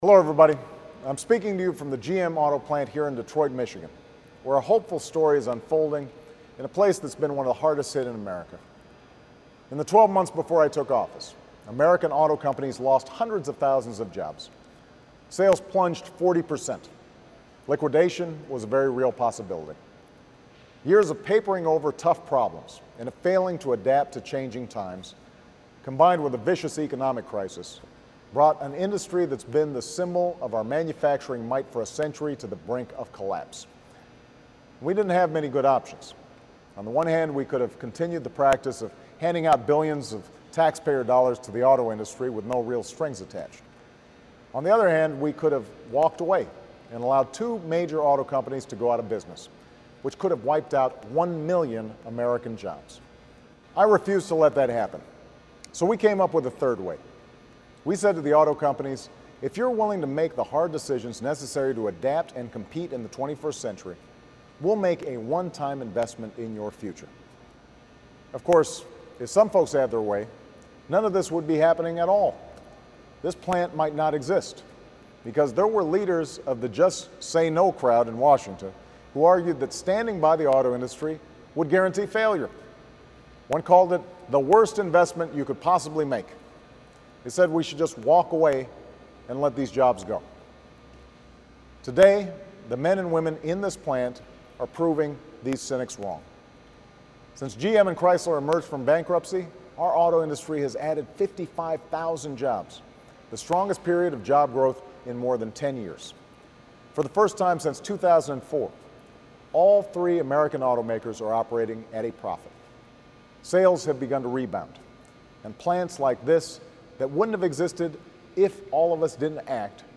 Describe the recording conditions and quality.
Detroit, Michigan